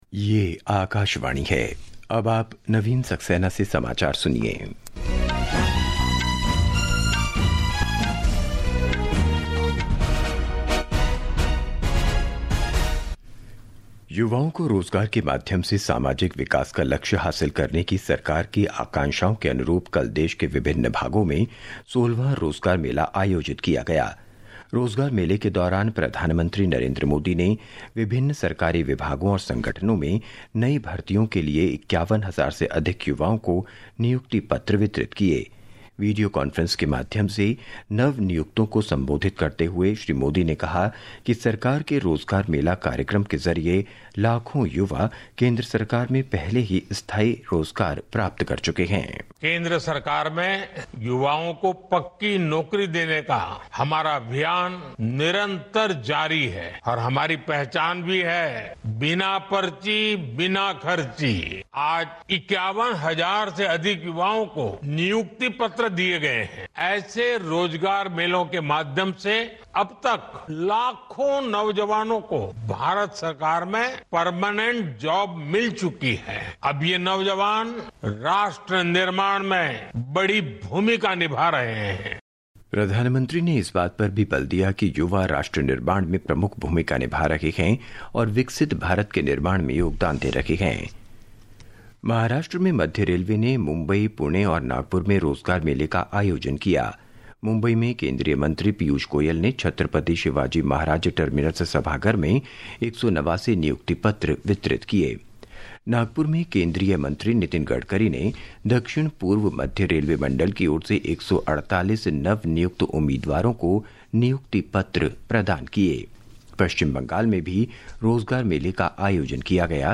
प्रति घंटा समाचार
प्रति घंटा समाचार | Hindi